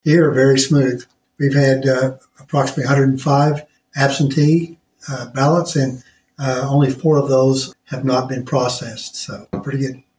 Election Board in Nowata County, and says how he thought everything went on election day.